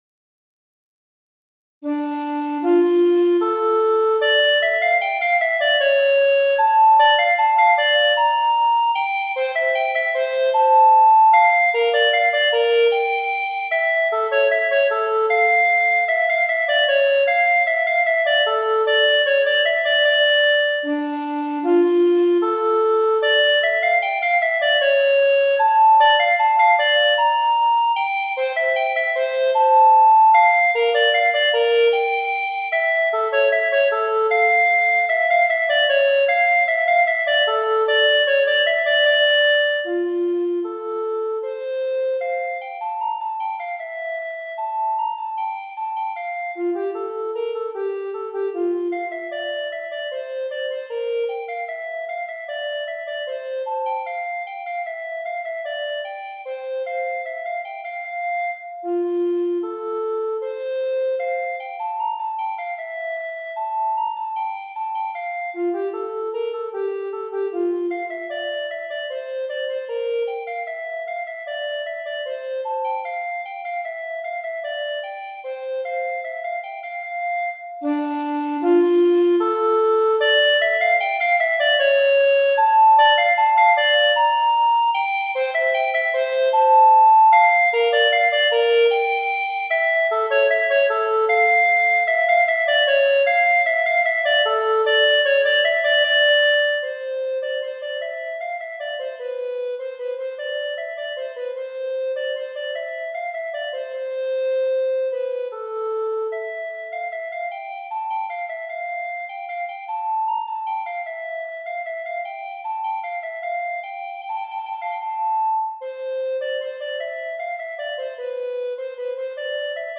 Flûte à Bec Solo